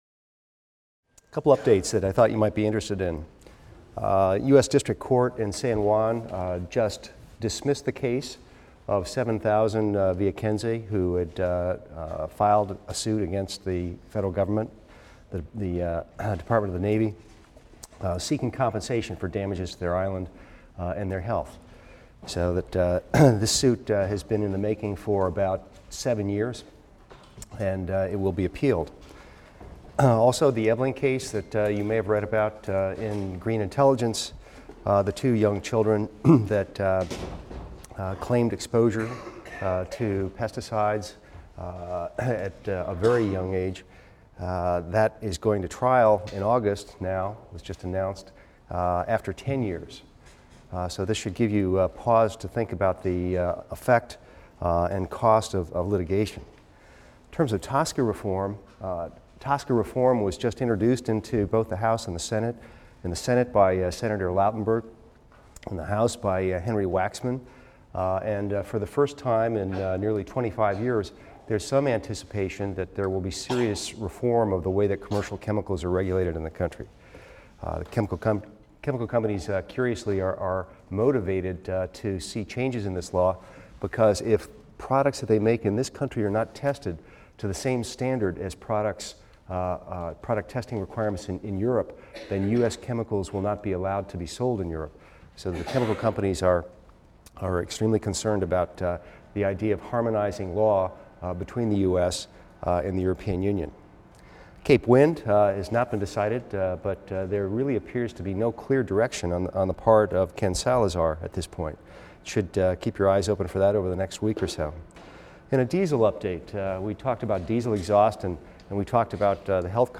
EVST 255 - Lecture 24 - Reflection and Lessons | Open Yale Courses